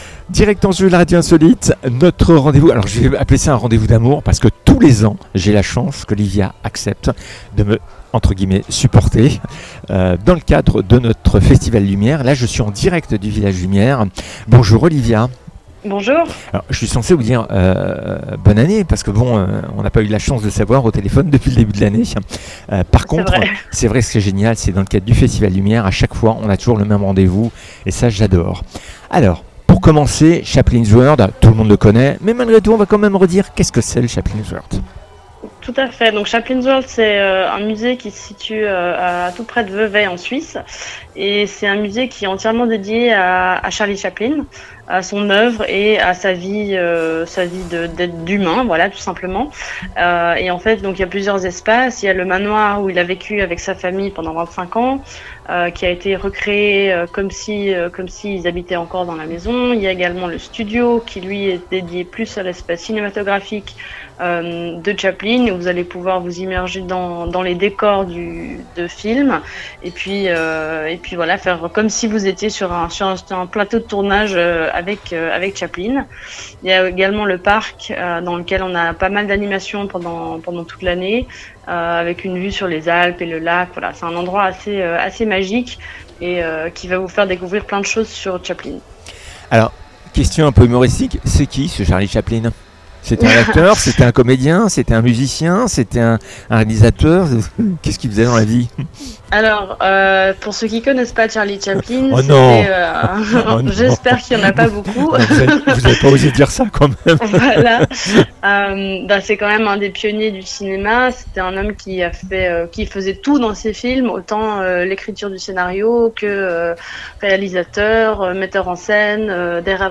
Interview en direct du Village Lumière dans le cadre du 17ème Festival Lumière